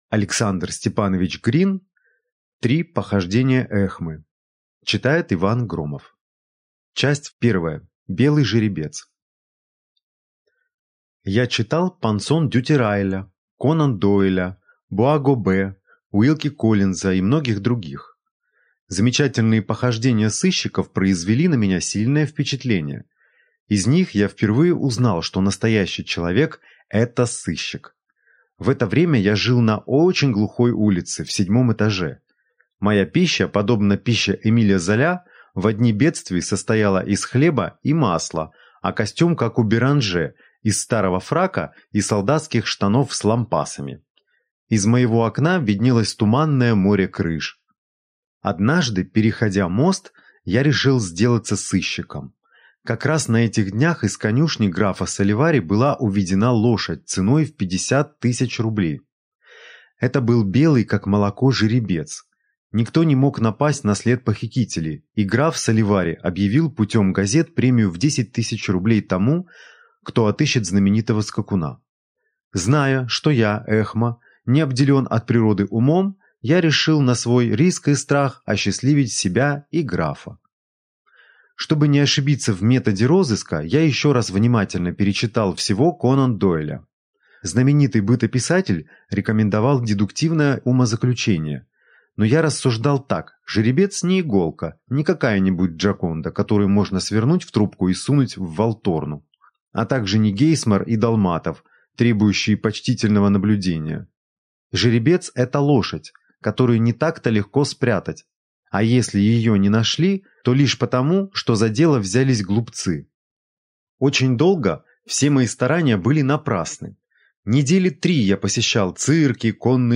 Аудиокнига Три похождения Эхмы | Библиотека аудиокниг